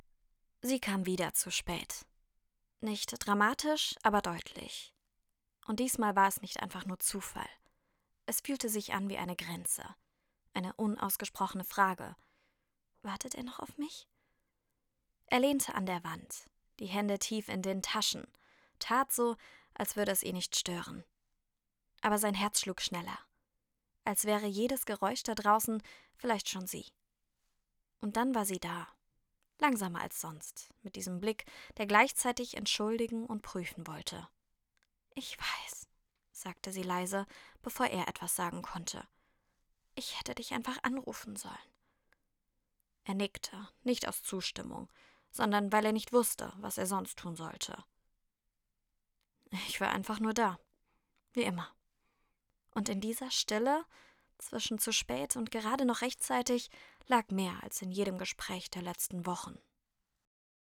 Emotionale Stimme auf Deutsch & Englisch.
• weiblich
• emotional | dynamisch | sanft |
• Junge, frische Klangfarbe, die natürlich und sympathisch klingt
HÖRBUCH